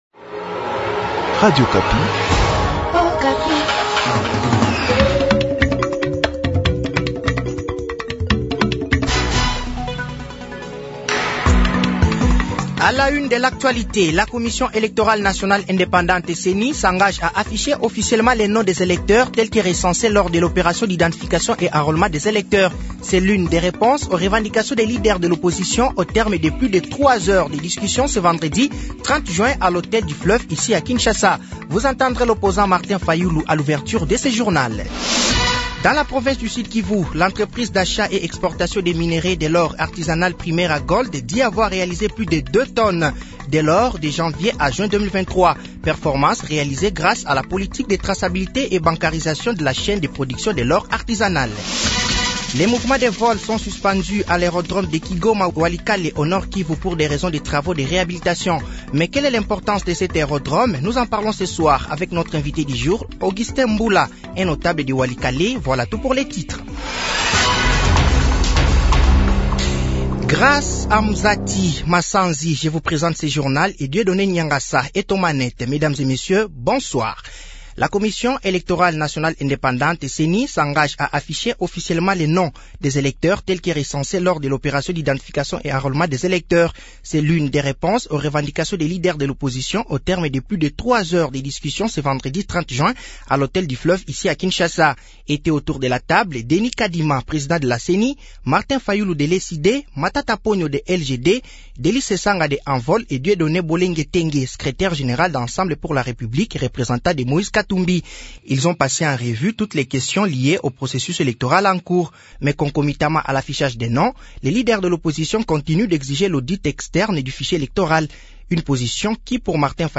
Journal français de 18h00 de ce dimanche 30/06/2023